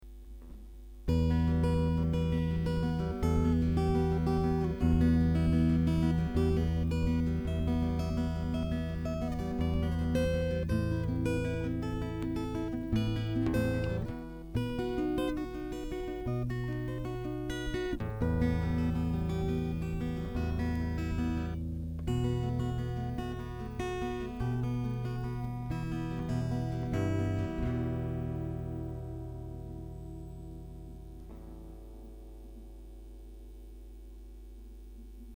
Alors voila deux trois samples (massacres) de ma petite gratte une Ibanez PF60Ce, rien à voir avec les canons du topic .
C'est enregistré avec la gratte branchée directementen jack et Audacity.